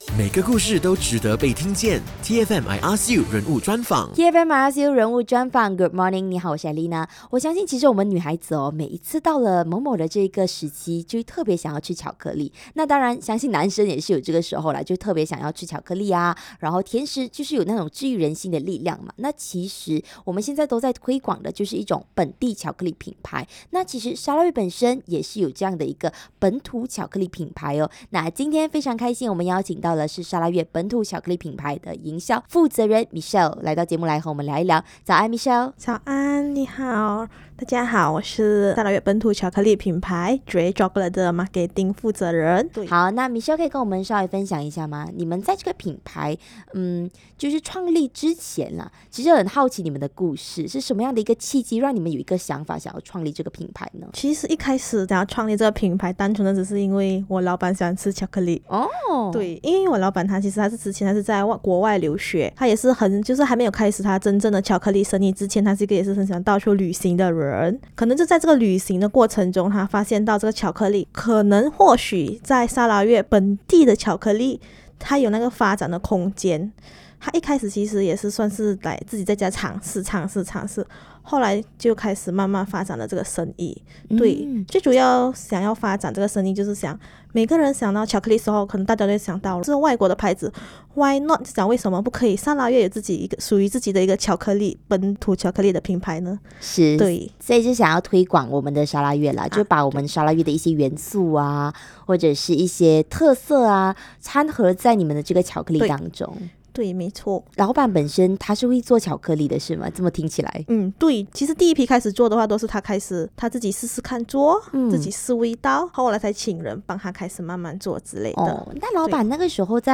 0115 Tea FM I Ask U 人物专访 砂拉越本土巧克力品牌 .mp3